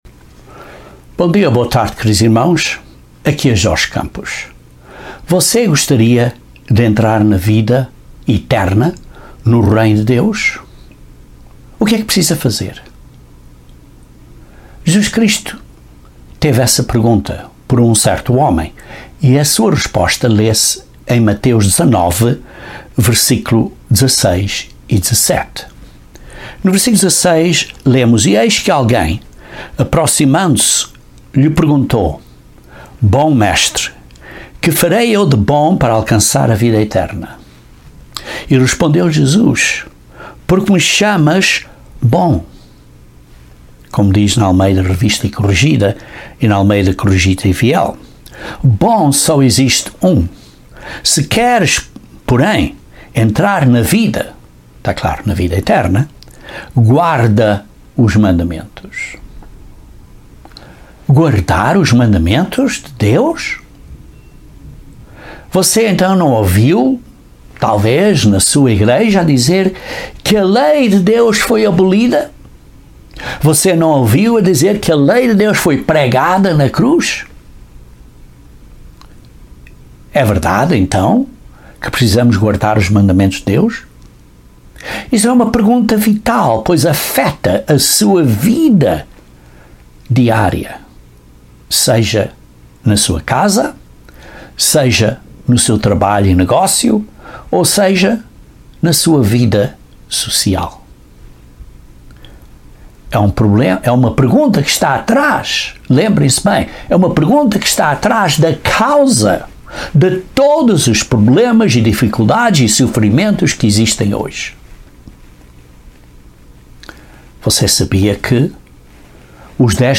Gostaria de herdar a vida eterna no Reino de Deus? Este sermão aborda como pode ter a vida eterna e que passos dar para ter essa grande dávida de Deus.